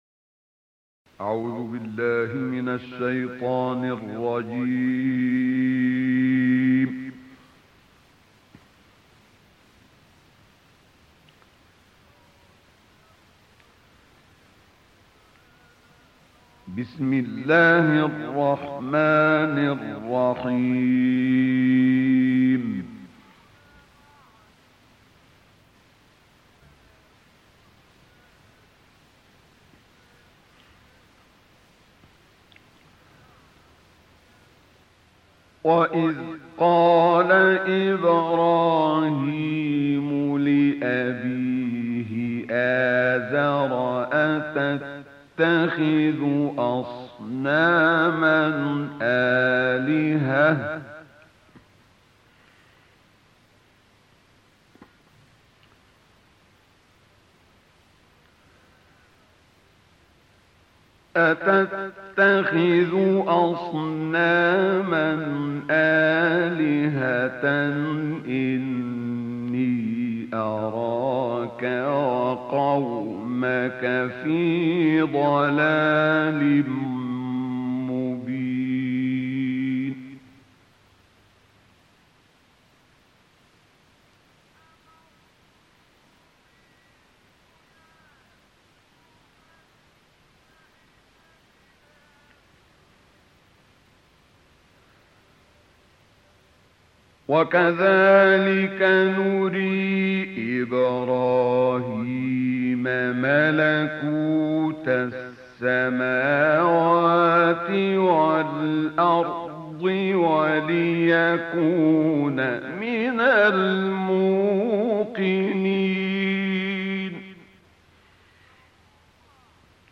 تلاوت استاد شیخ عبدالفتاح الشعشاعی آیات 74 تا 96 سوره انعام